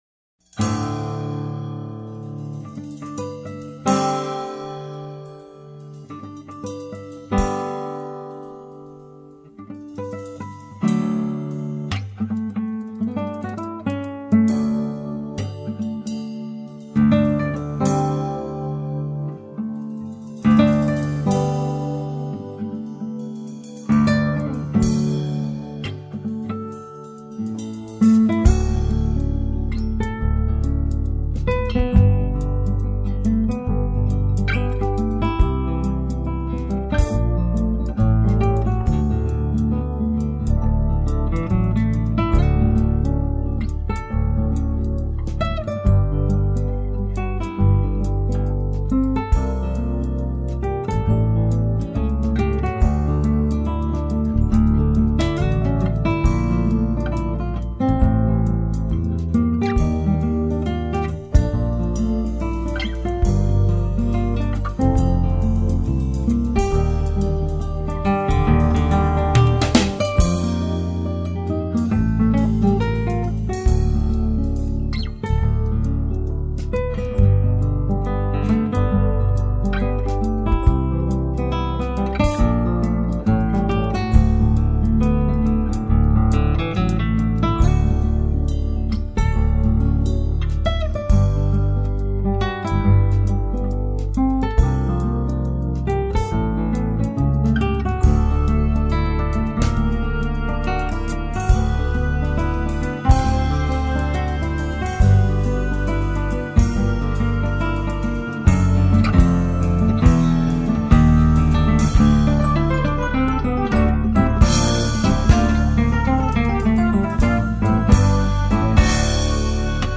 329   03:49:00   Faixa:     Instrumental